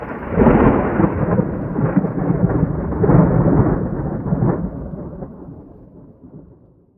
Thunder 4.wav